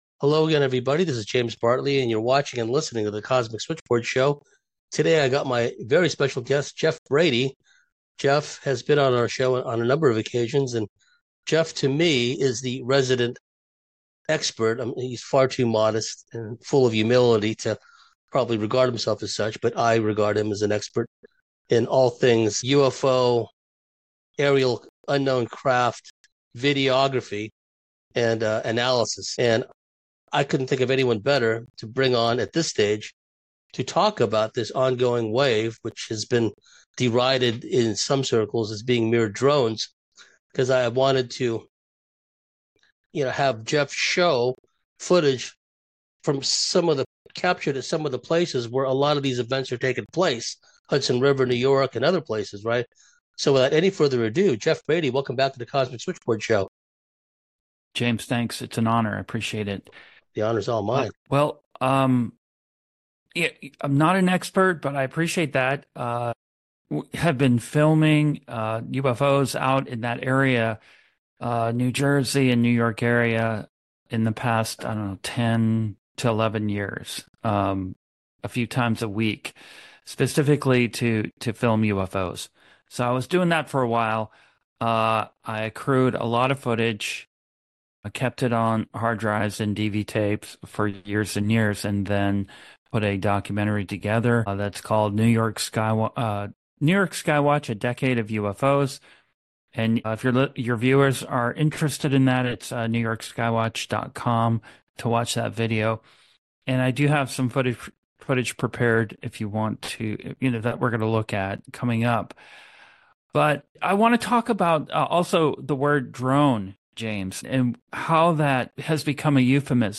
The interview also goes into the depopulation agenda linked to alien technology and health concerns.